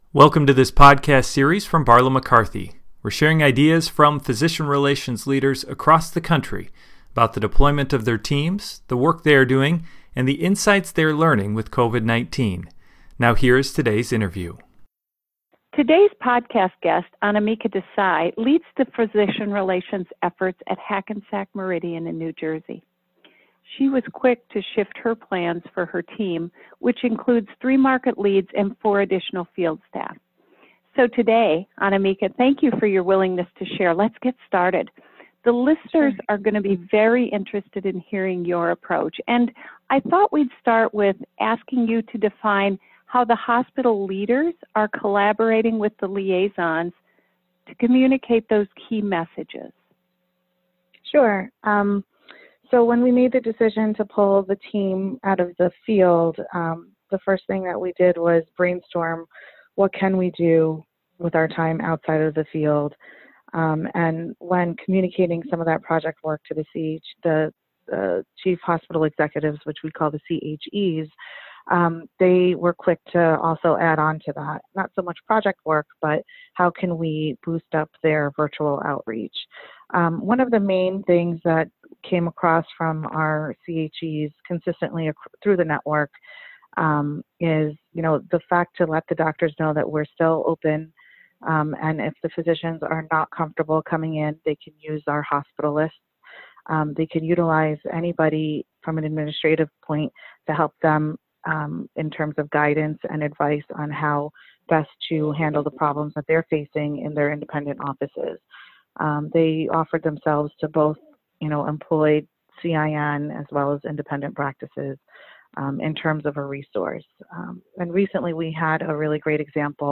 B/Mc Podcast: COVID-19 and Physician Relations – Leadership Interview #1